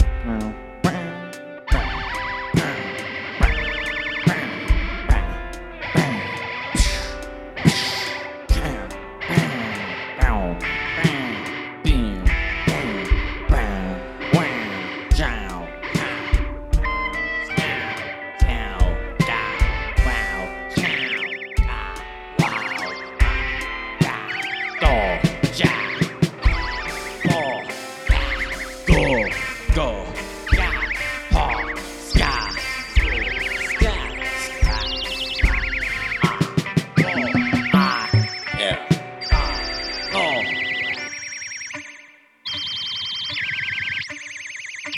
The May sessions were pretty much just one continuous improv with no planning or coherence.